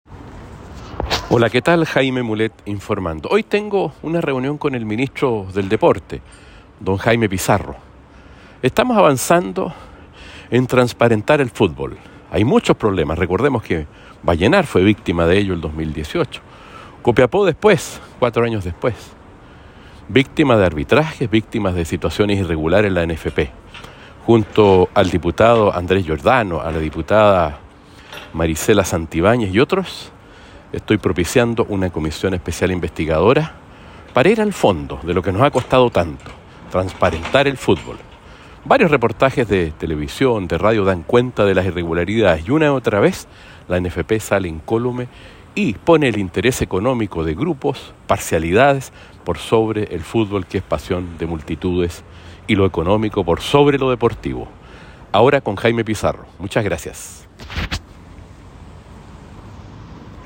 Diputado Jaime Mulet, comenta reunión que sostuvo con el ministro del Deporte, Jaime Pizarro.